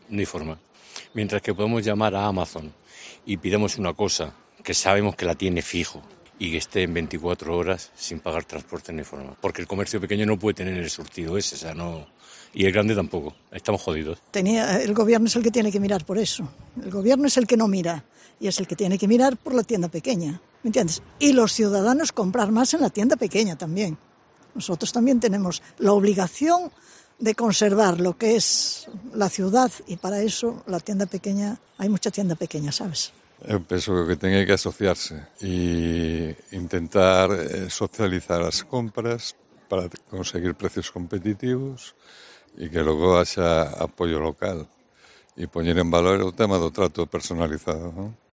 Los ciudadanos opinan sobre el comercio local de Ferrol